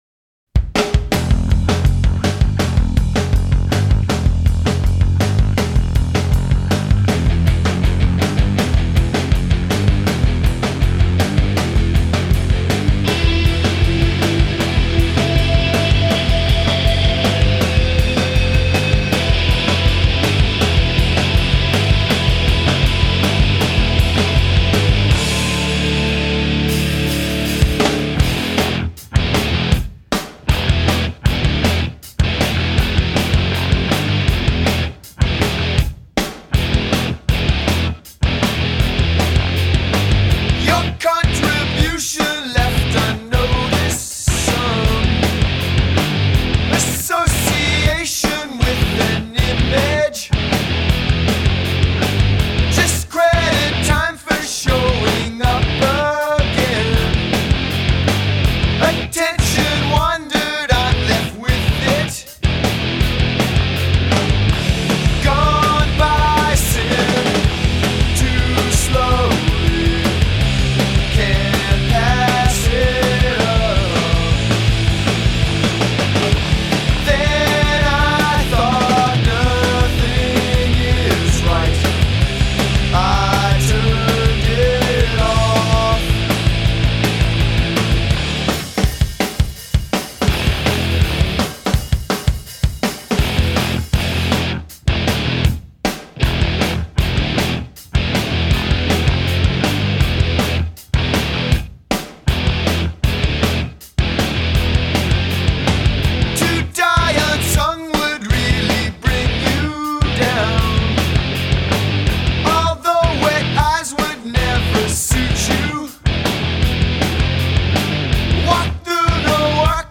loud, nasty, groove-based hardcore
brutal, brass tacks awesomeness